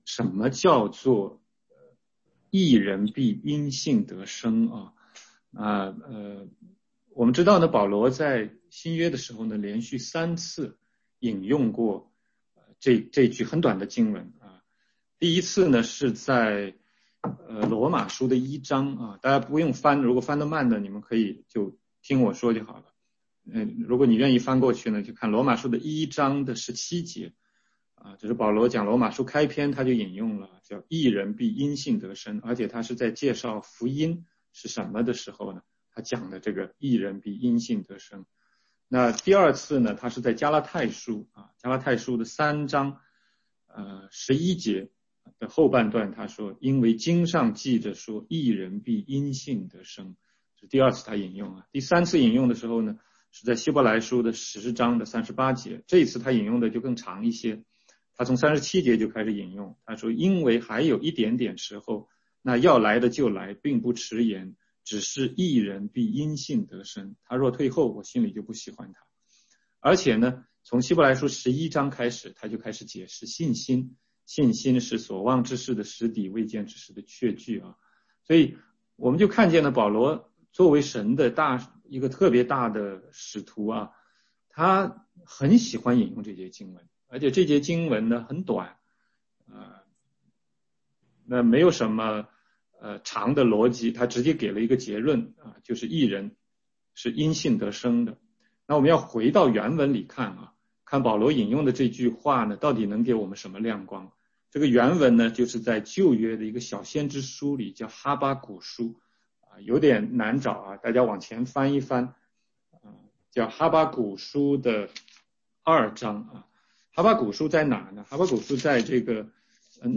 16街讲道录音 - 义人必因信而生